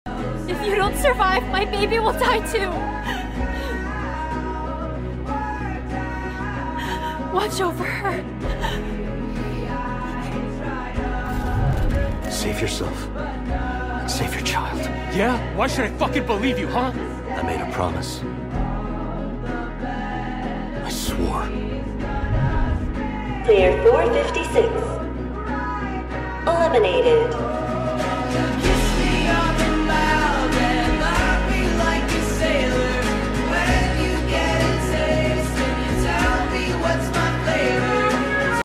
Slowed & Reverb